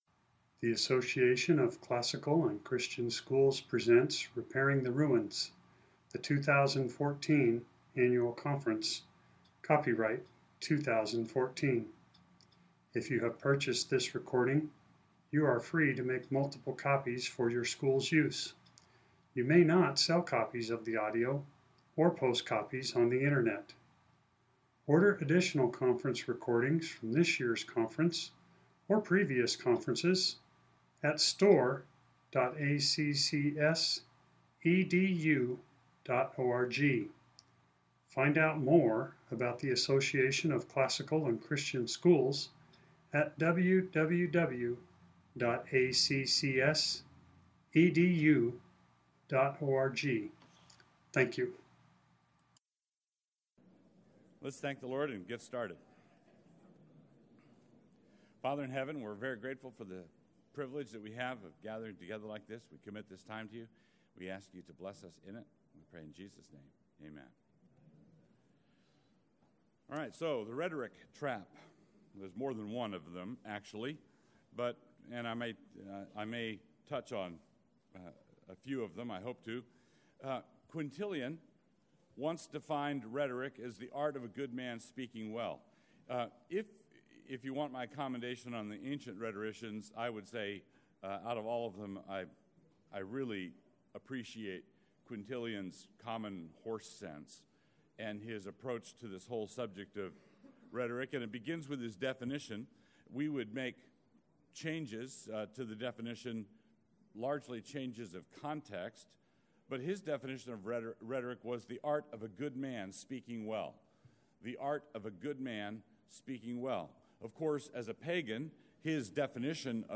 2014 Workshop Talk | 2014 | 7-12, Rhetoric & Composition
Jan 19, 2019 | 7-12, Conference Talks, Library, Media_Audio, Rhetoric & Composition, Workshop Talk | 0 comments